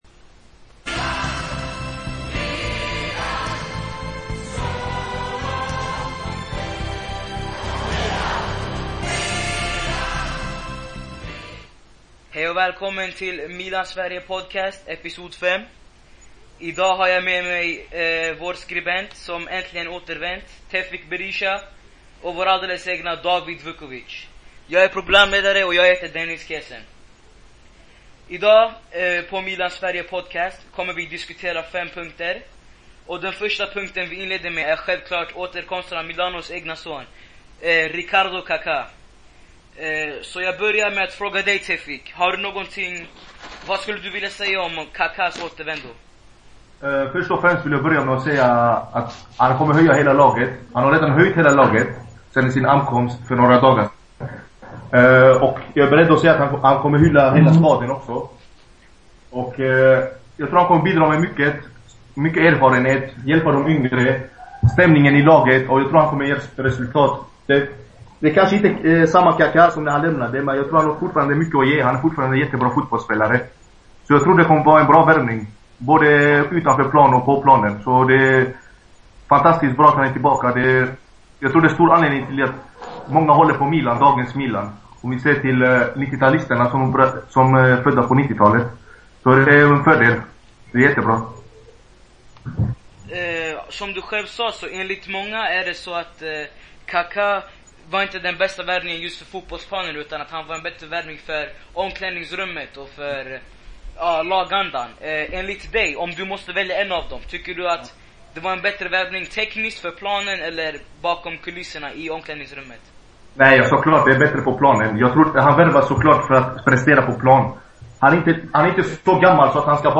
Ett par ur redaktionen kommer varje vecka sätta sig ner och diskutera tillsammans i cirka 25 min.